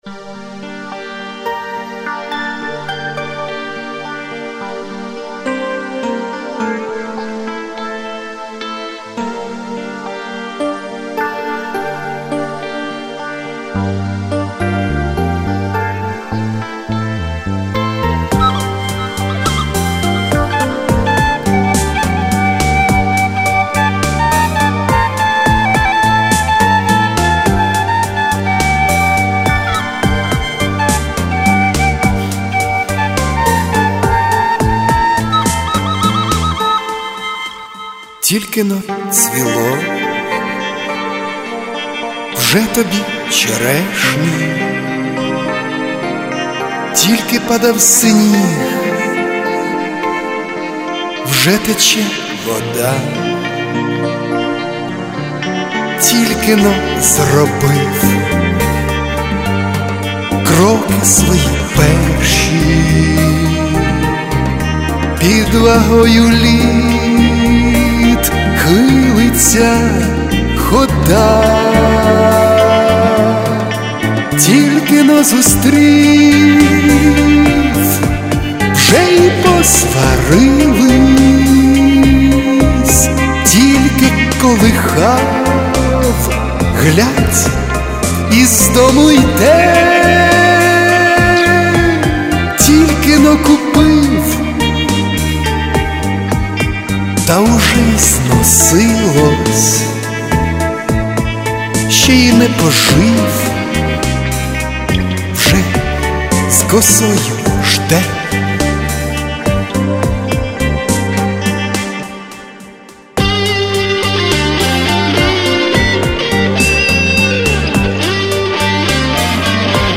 Рубрика: Поезія, Авторська пісня
І голос Ваш такий м'який, теплий приємний!
Живе музикування особливо цінне!